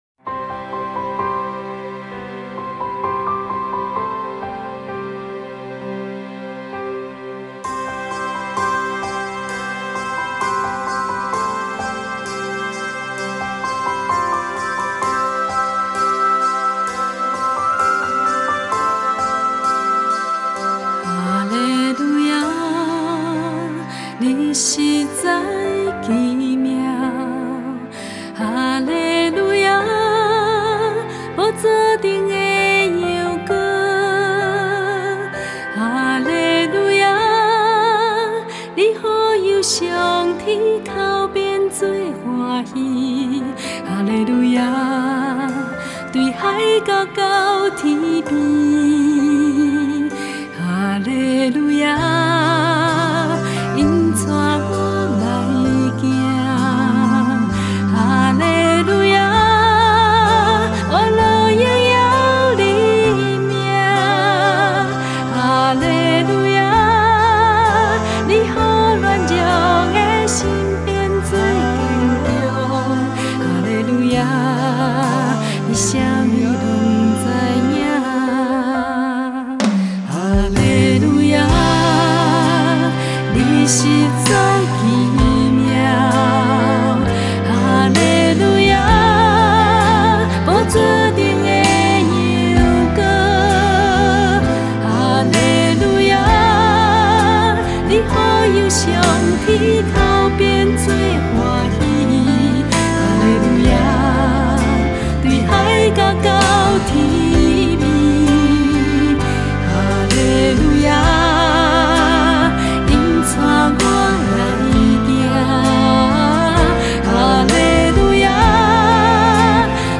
前奏 → 主歌 1 → 主歌 2 → 主歌 1 → 主歌 3 → 主歌 1 → 主歌 3 → 尾段